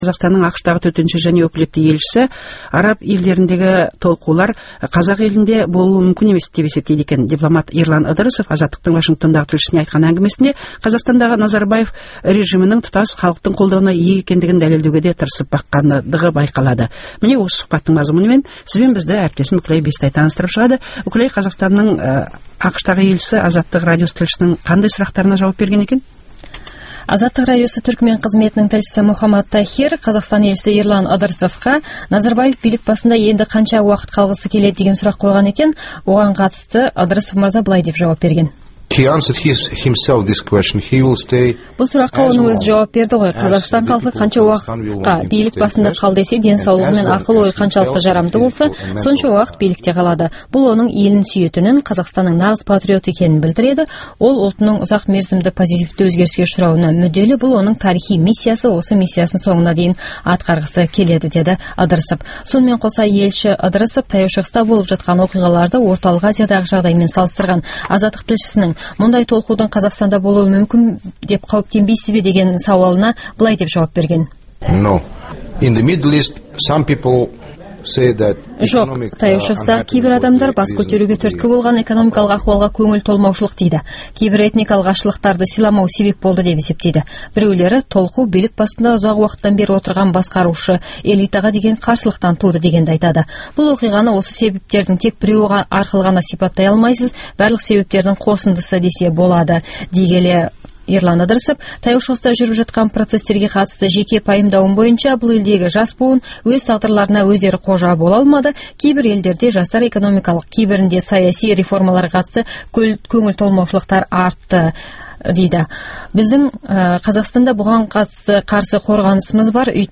Ерлан Ыдырысовтың сұқбатын қысқаша тыңдаңыз